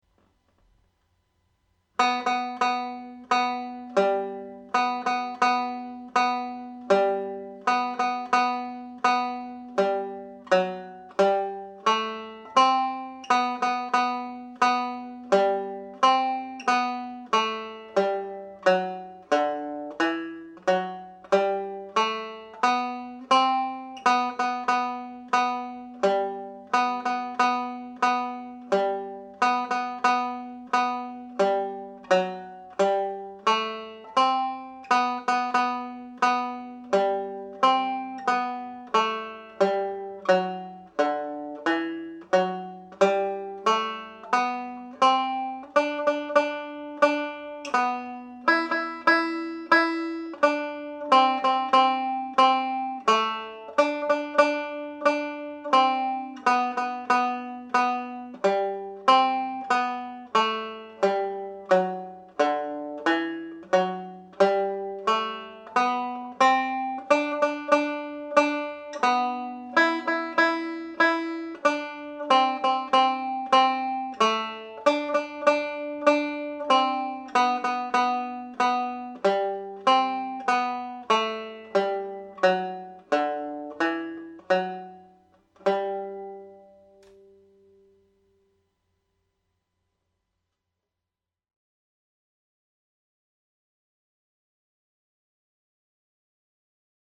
• banjo scale
The Fairy Dance reel played slowly with triplets added